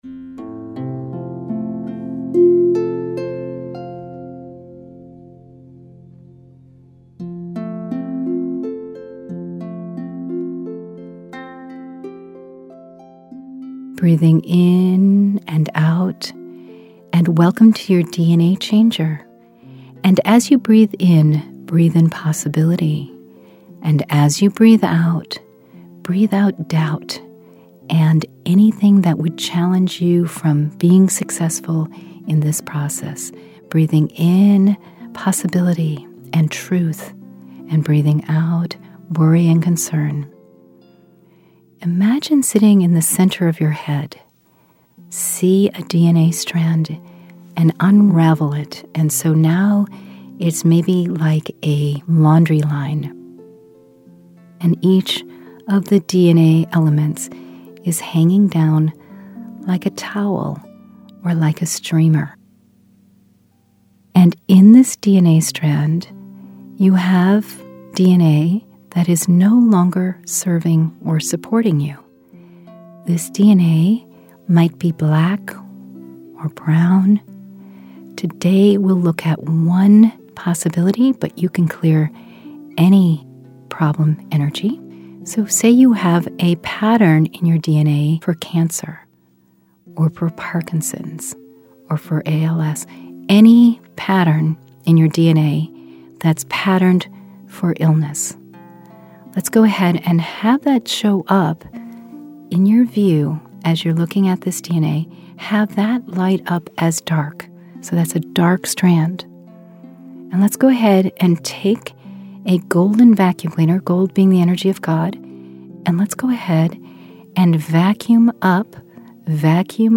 3 Q5 Meditations: